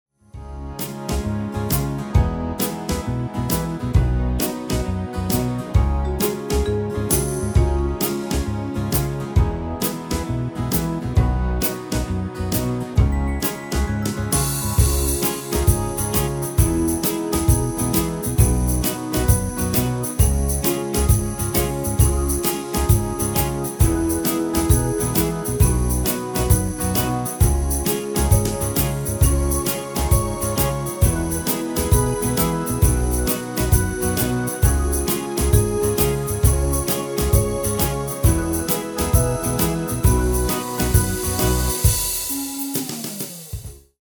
Demo/Koop midifile
- GM = General Midi level 1
- Géén vocal harmony tracks
Demo's zijn eigen opnames van onze digitale arrangementen.